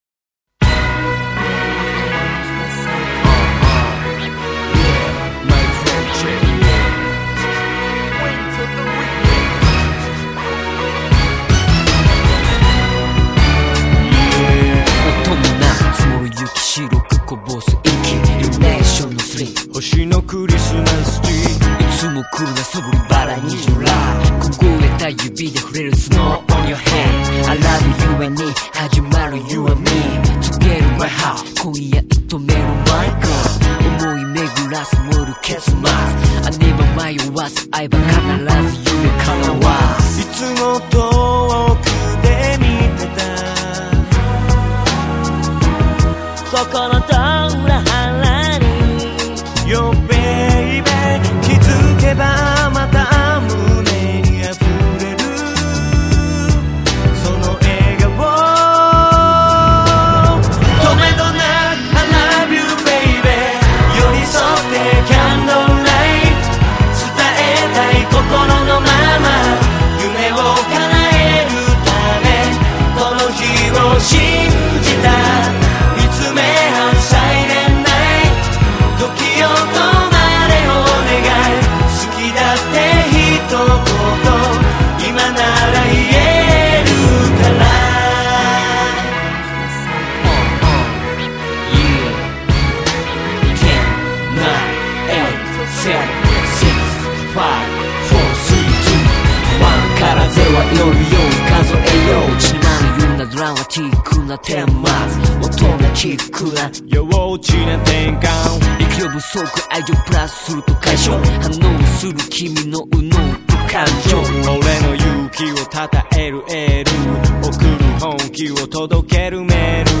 От чего-то она всегда навевает мне праздничное настроение.